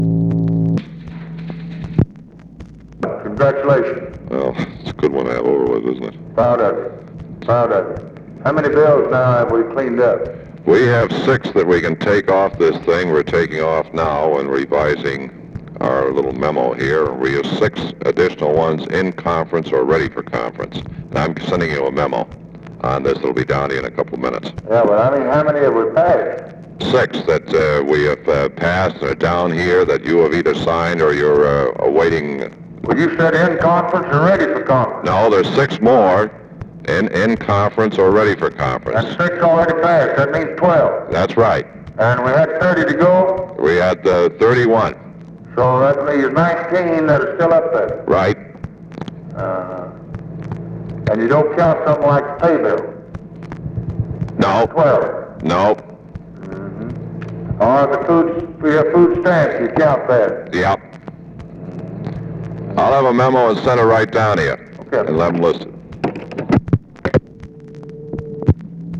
Conversation with LARRY O'BRIEN, July 1, 1964
Secret White House Tapes